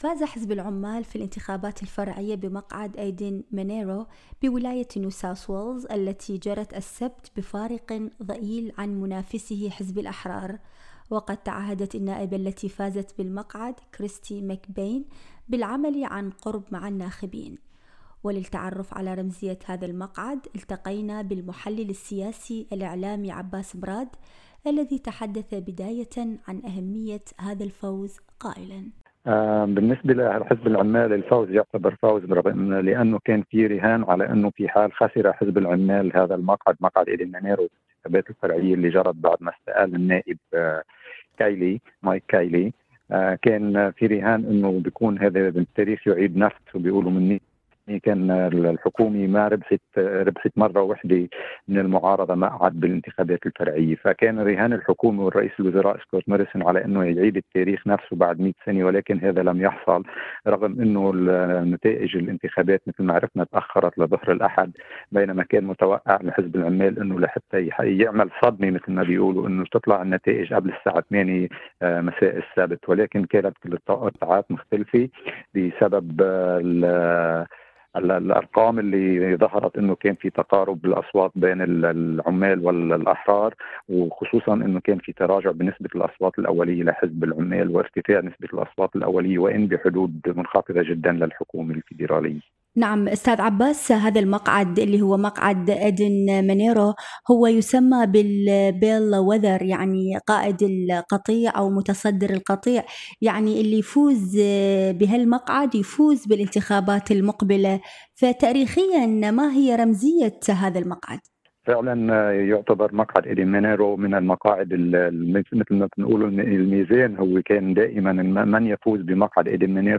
التقينا